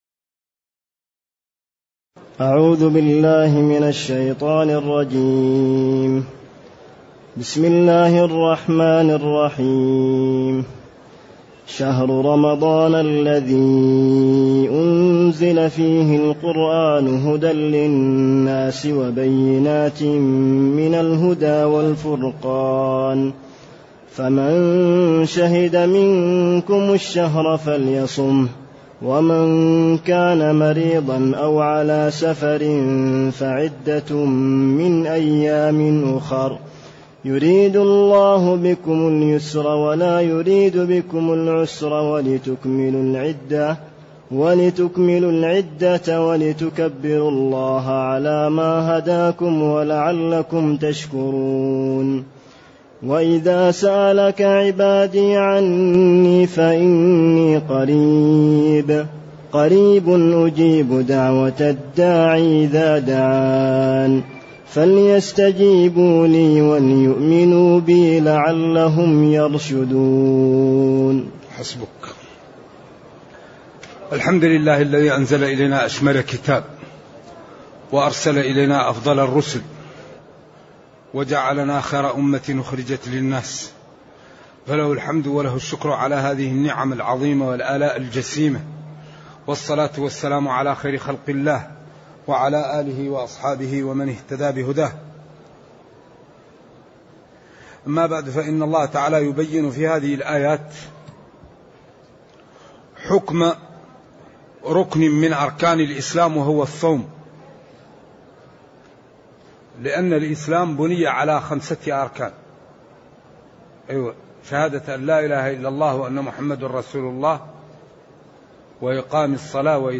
تاريخ النشر ٥ شعبان ١٤٢٨ هـ المكان: المسجد النبوي الشيخ